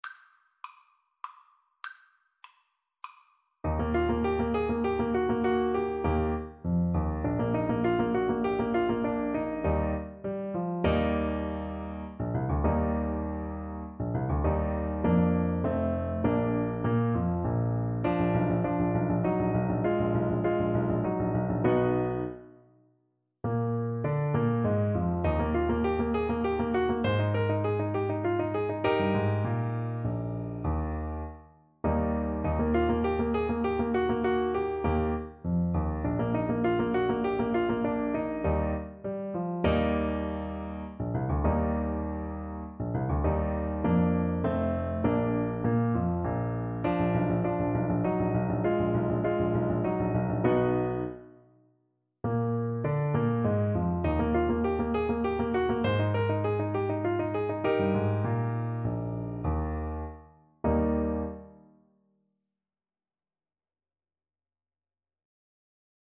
Eb major (Sounding Pitch) C major (Alto Saxophone in Eb) (View more Eb major Music for Saxophone )
3/4 (View more 3/4 Music)
Classical (View more Classical Saxophone Music)
paganini_minuetto_ASAX_kar1.mp3